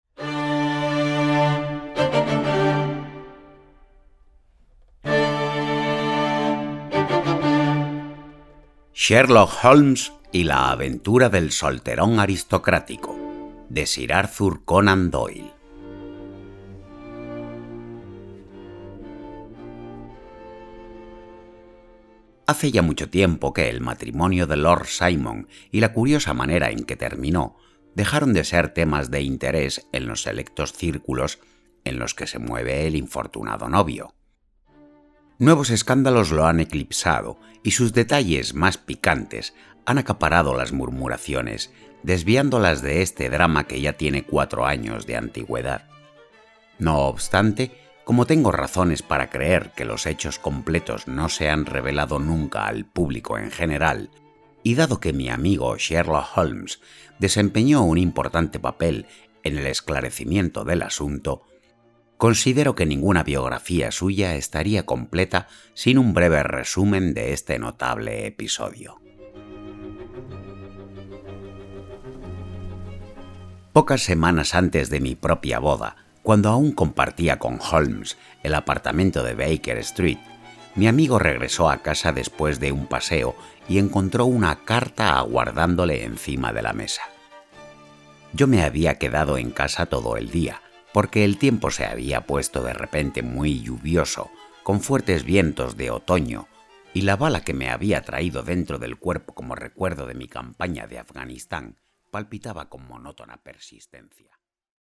Ambientación sonora, FX y selección musical:
Escuchar Demo: